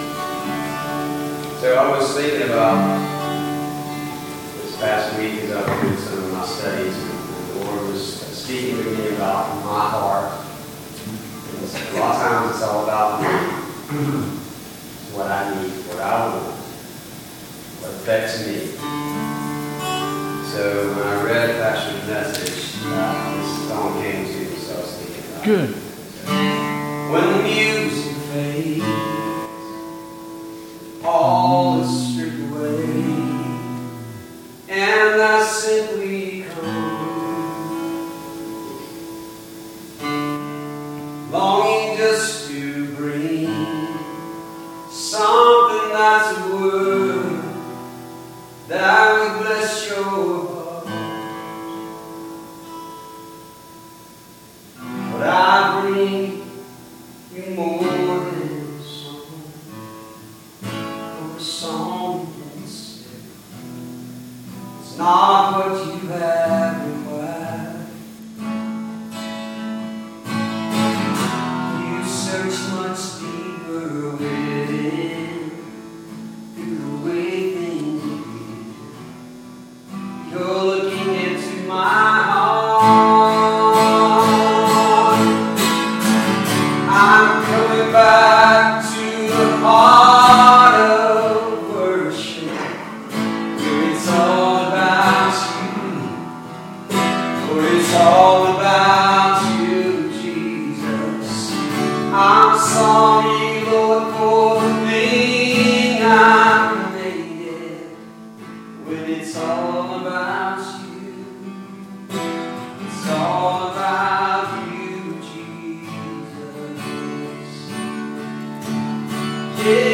2022 Bethel Covid Time Service
Music Special